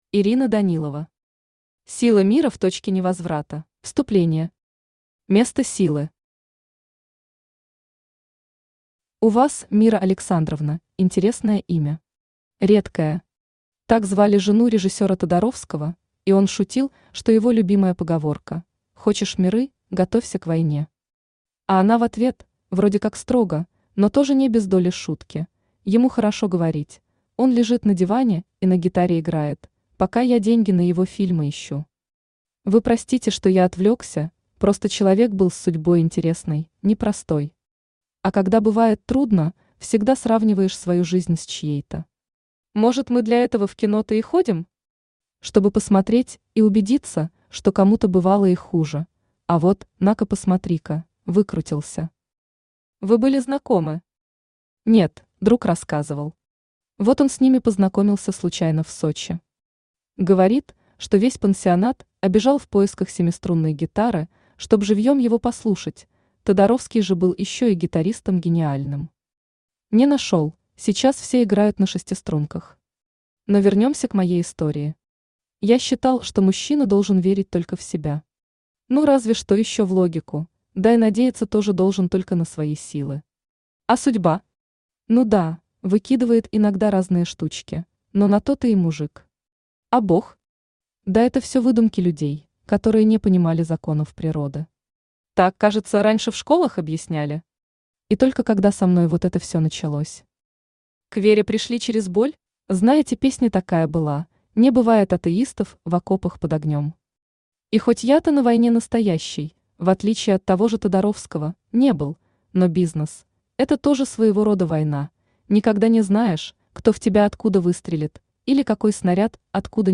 Аудиокнига Сила Мира в точке невозврата | Библиотека аудиокниг
Aудиокнига Сила Мира в точке невозврата Автор Ирина Данилова Читает аудиокнигу Авточтец ЛитРес.